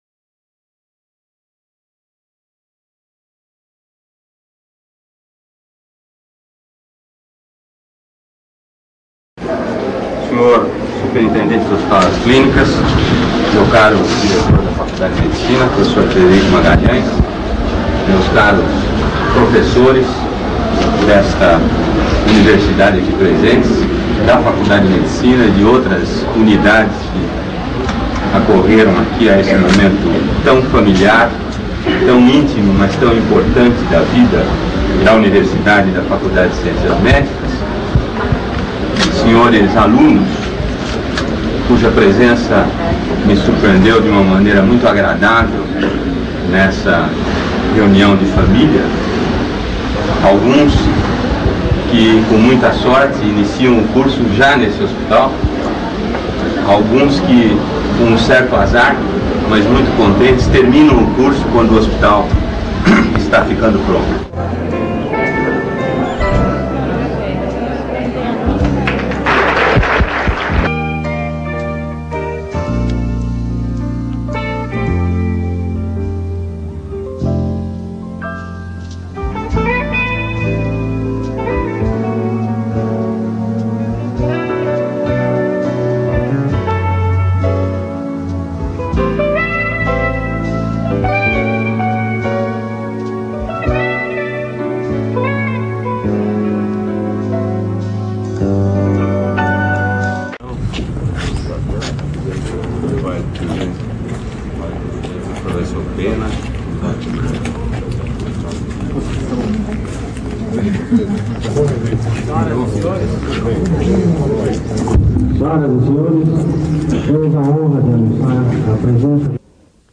Solenidade de inauguração do Hospital de Clínicas da Unicamp com a presença do Reitor José Aristodemo Pinotti.
Discursos e entrevistas.
Solenidade de inauguração do Ginásio Multidisciplinar de Esportes da Unicamp com a presença do Ministro da Cultura Aluísio Pimenta.